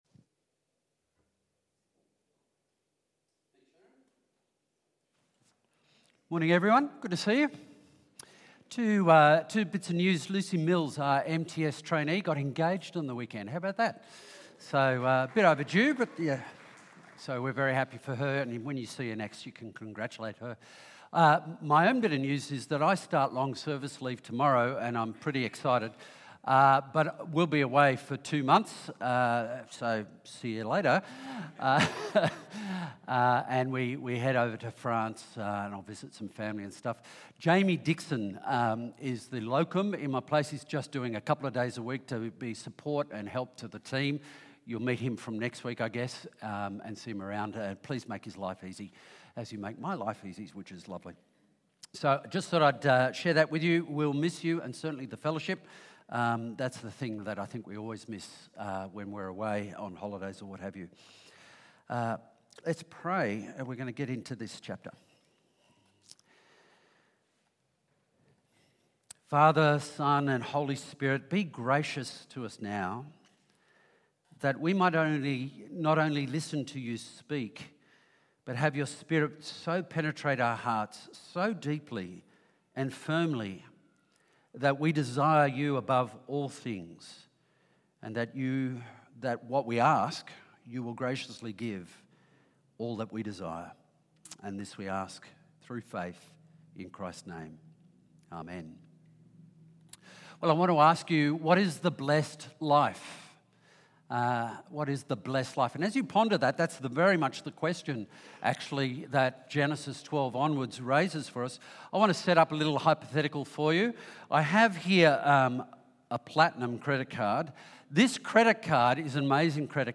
The sermon focused on God’s covenant with Abram in Genesis 15, highlighting trust and faith in God’s promises despite doubts. It emphasised that salvation is a gift gained through faith, not deeds, and that believers can find confidence in God’s faithfulness.